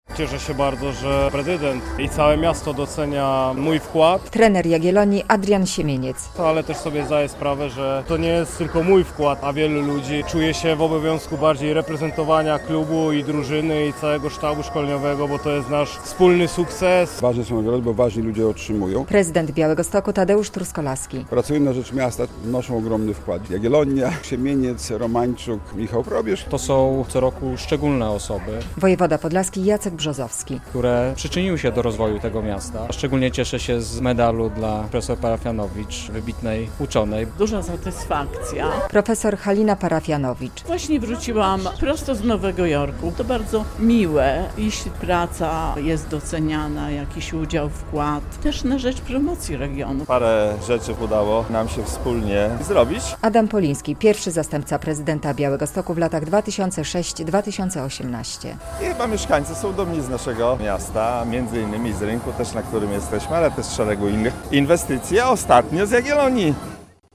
Prezydent Tadeusz Truskolaski wręczył medale tym, którzy szczególnie przysłużyli się rozwojowi i promocji Białegostoku. Uroczystość odbyła się w sobotę (22.06) na Rynku Kościuszki w ramach Dni Miasta.